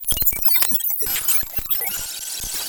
analog bleep cartoon commnication computer computing crash data sound effect free sound royalty free Movies & TV